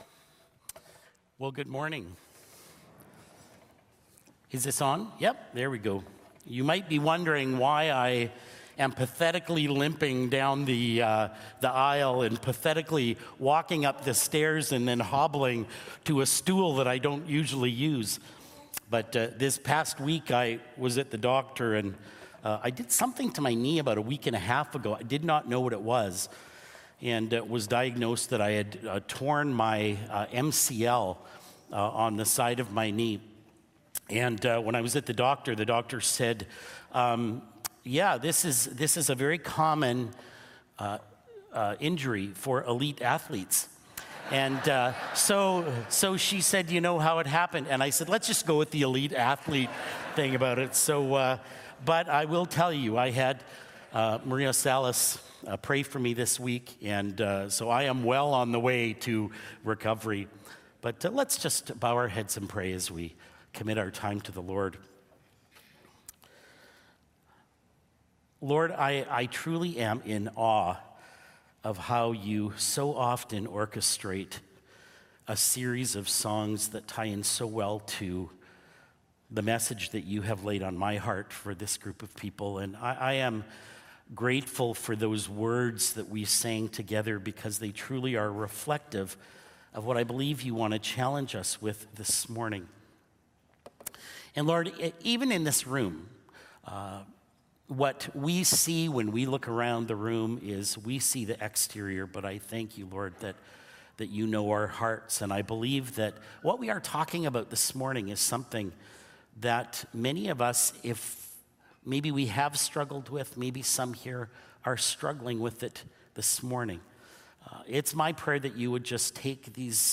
Podcast for messages from Whyte Ridge Baptist Church in Winnipeg, Manitoba, Canada.
Whyte Ridge Baptist Church Sermons